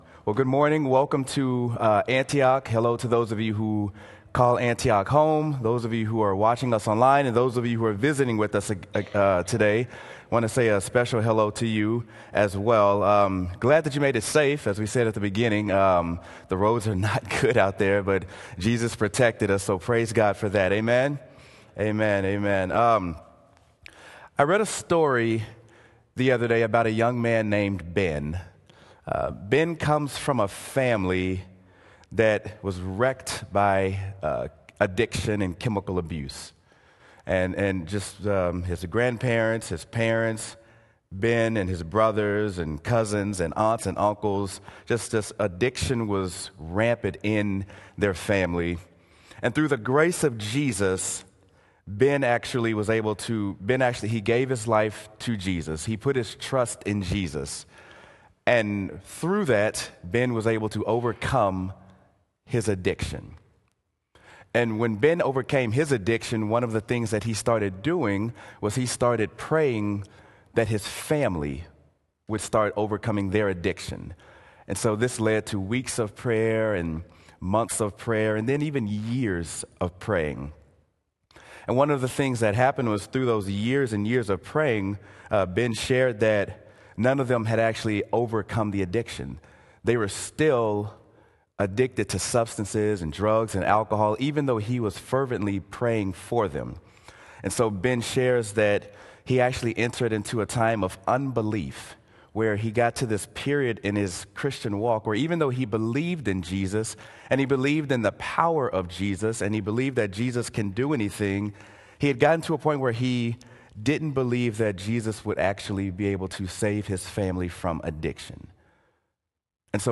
Sermon: Mark: Help My Unbelief!
sermon-mark-help-my-unbelief.m4a